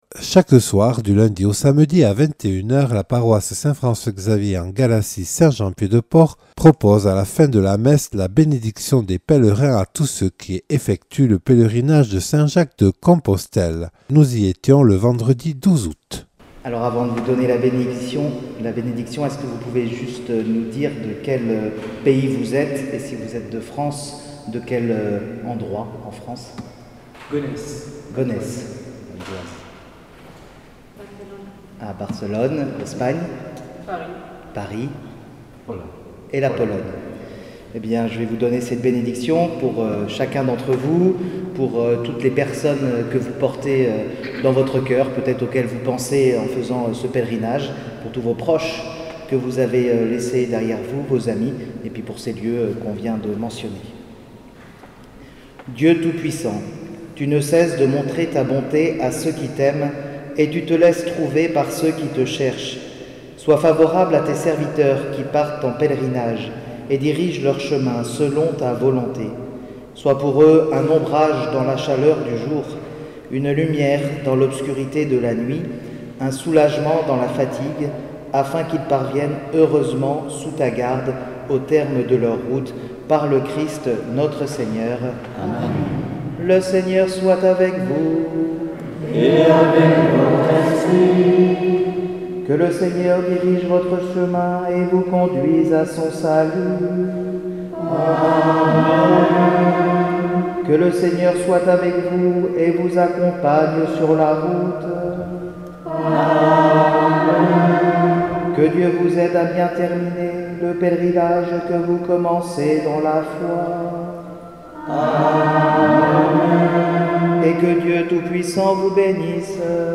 Reportage réalisé le 12 août 2022 lors de la messe en l'église Notre-Dame.